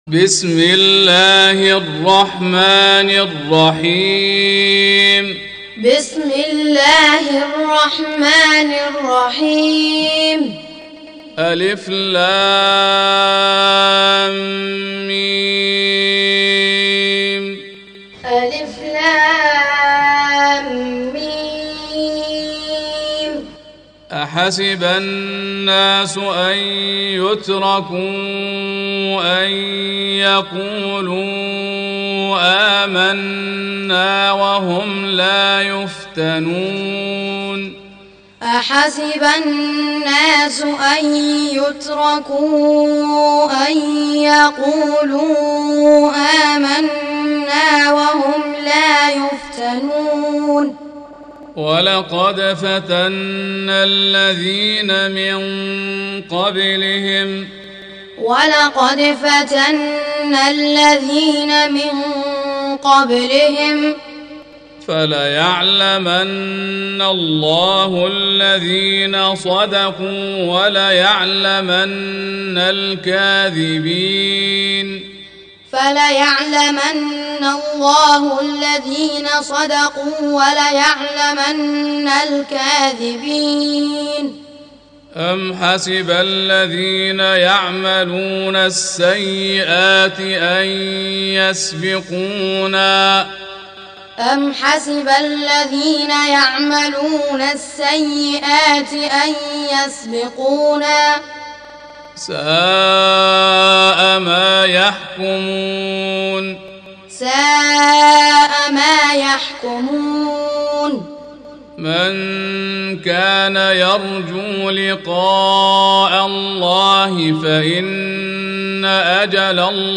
Surah Repeating تكرار السورة Download Surah حمّل السورة Reciting Muallamah Tutorial Audio for 29. Surah Al-'Ankab�t سورة العنكبوت N.B *Surah Includes Al-Basmalah Reciters Sequents تتابع التلاوات Reciters Repeats تكرار التلاوات